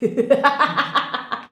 LAUGH 2.wav